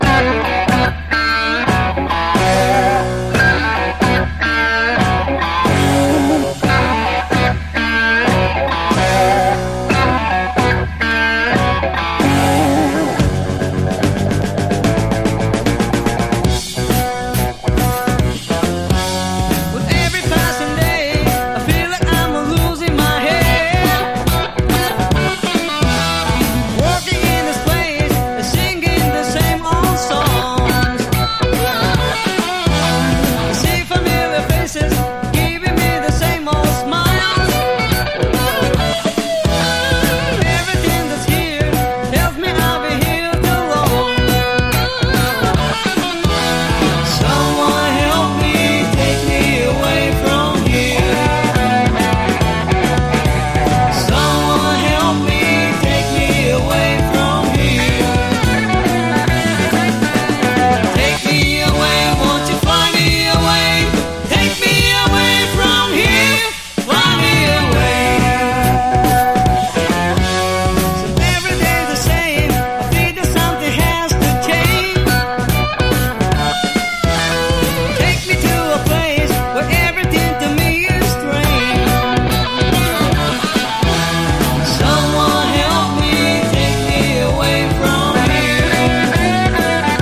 衝撃のファースト・アルバムから更にパワーアップした、ハード・ロックの真髄を背骨に伝える脳天直撃アルバム！